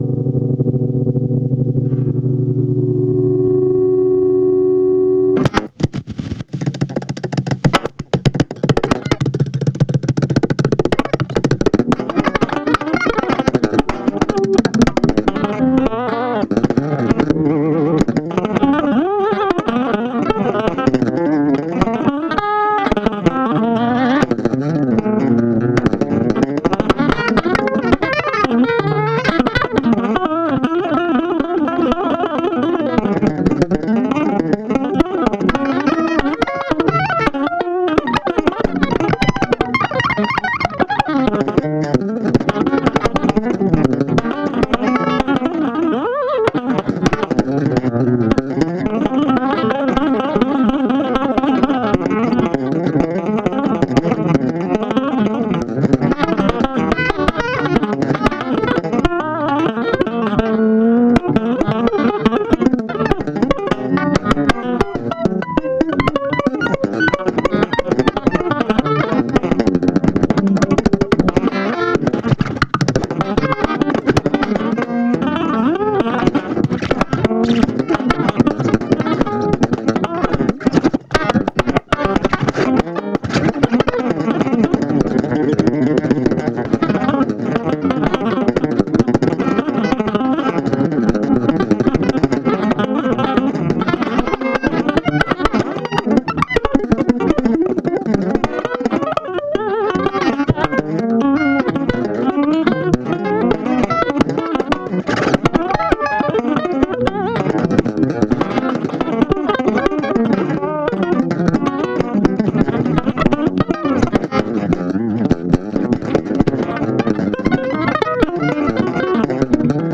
エフェクターはCranetortoiseの”GC-1”（コンプレッサー）のみ。
アンプは最近愛用のZT Amp社の”Lunchbox”。
それを大音量で鳴らし、マイク録音されました。